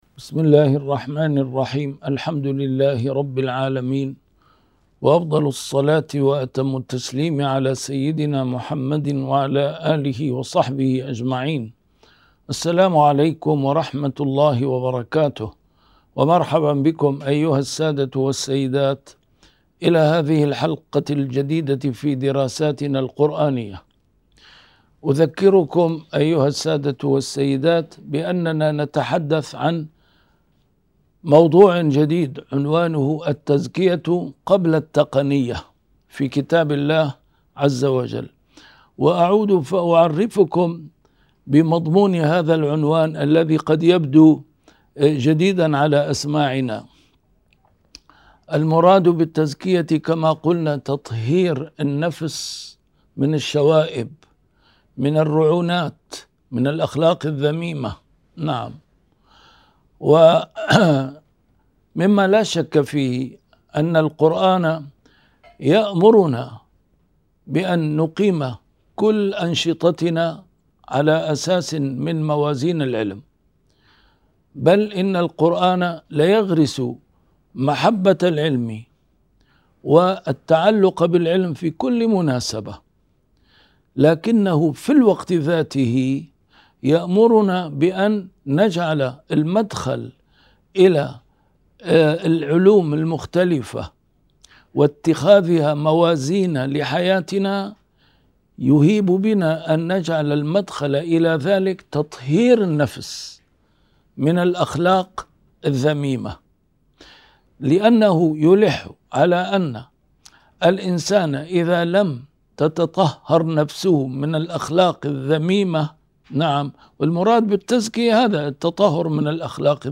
A MARTYR SCHOLAR: IMAM MUHAMMAD SAEED RAMADAN AL-BOUTI - الدروس العلمية - التزكية في القرآن الكريم - 9 - التمييز بين متطلبات الروح والنفس